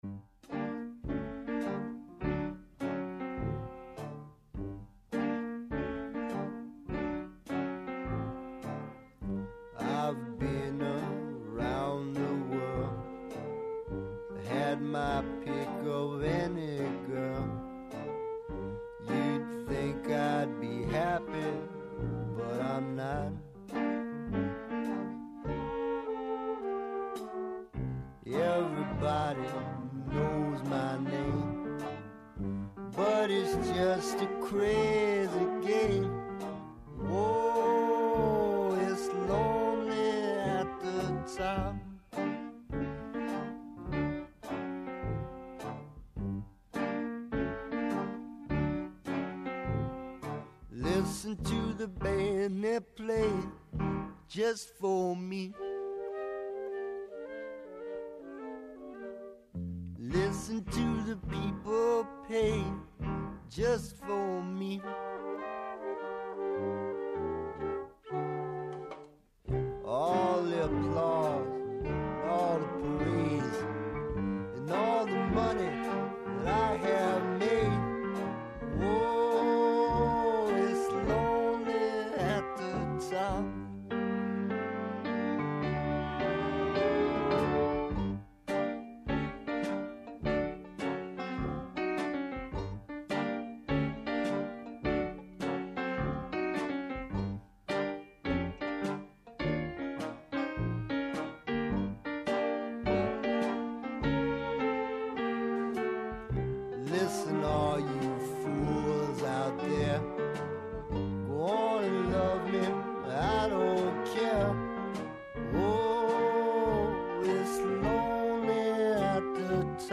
Την Πέμπτη 28 Μαρτίου στις 9 το βράδυ οι “Πλανόδιες Μουσικές” φιλοξενούν στο studio του Δευτέρου Προγράμματος, τον Γιώργο Κιμούλη με αφορμή το θεατρικό εργο του Αλεξάντερ Γκέλμαν “Παγκάκι” που παίζεται στο Θέατρο “Τόπος Αλλού”.